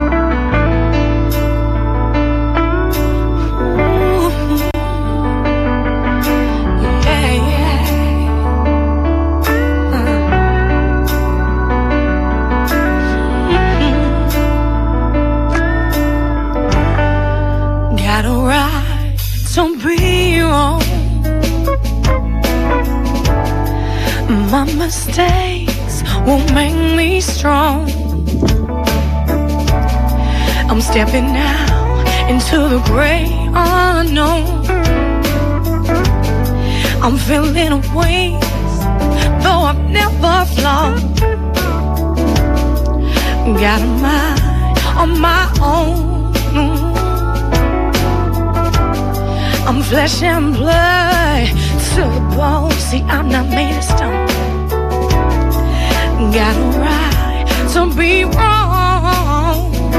британской соул-исполнительницы